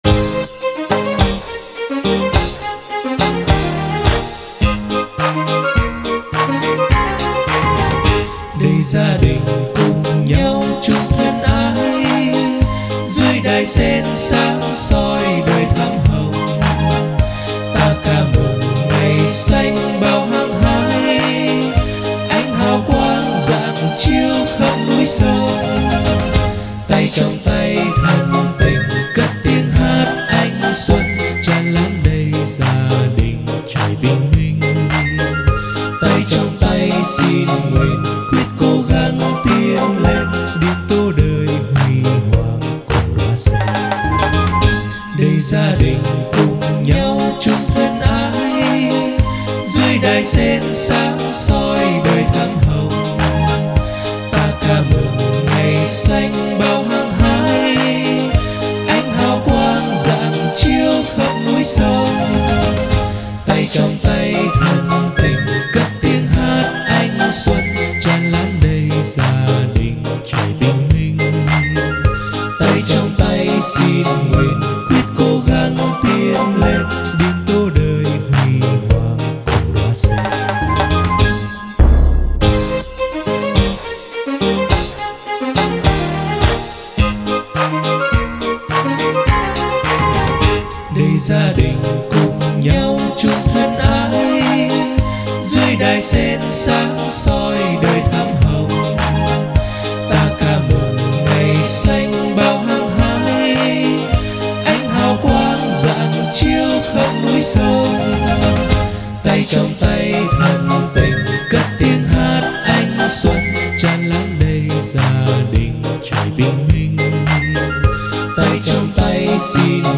Tam ca